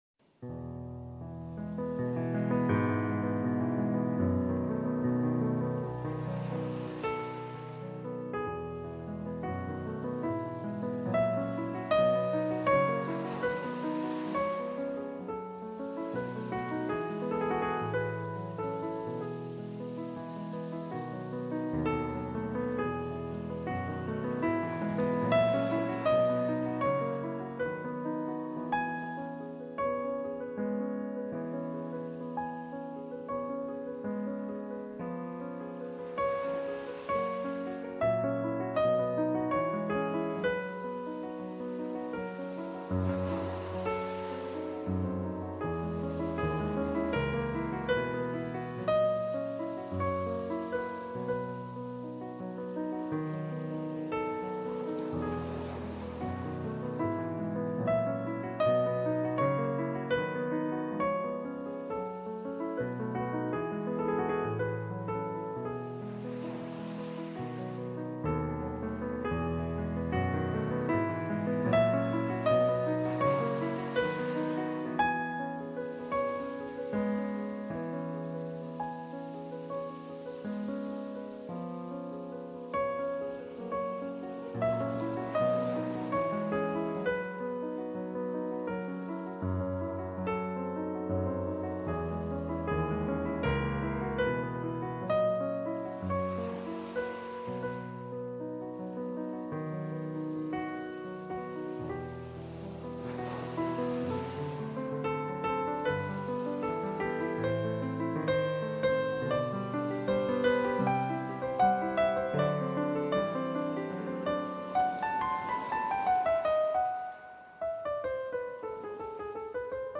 Mendelssohn – Piano instrumental para enfoque y relajación